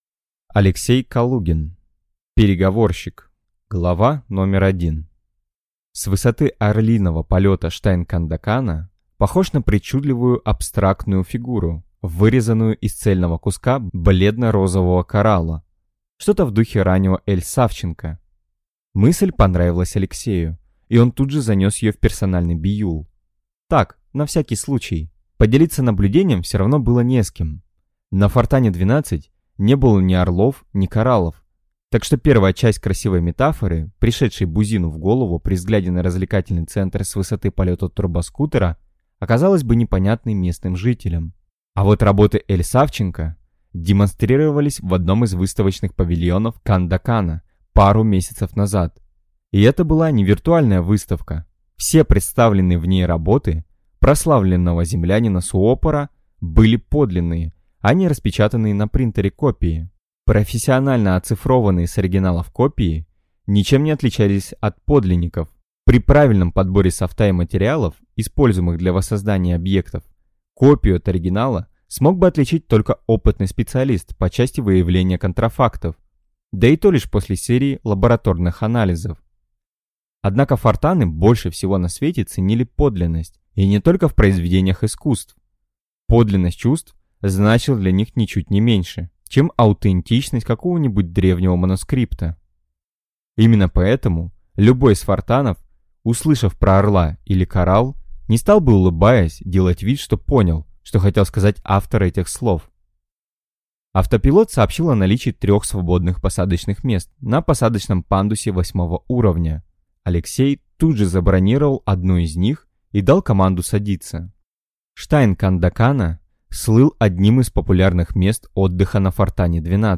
Аудиокнига Переговорщик | Библиотека аудиокниг